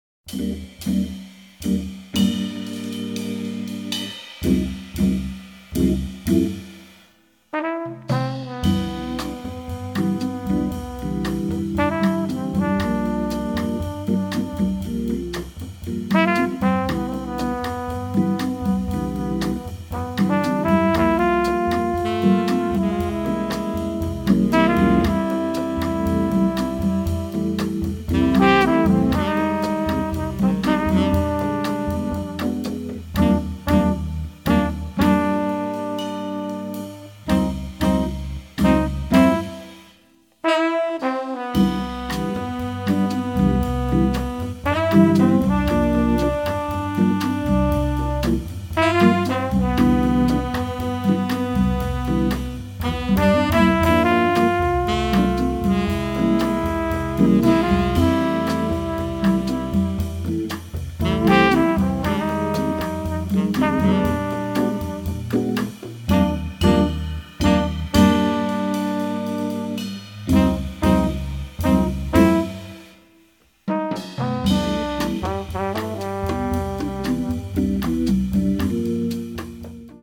tenor and baritone saxes, vocals
trumpet
drums
percussion
Melvin Rhyne – hammond B3 organ